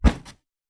Punching Box Intense E.wav